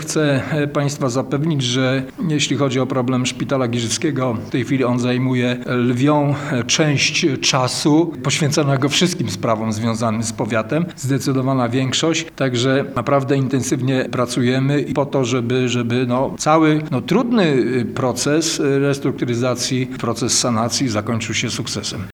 Dokładamy wszelkich starań, by pomyślnie rozwiązać problemy szpital – zapewnia starosta.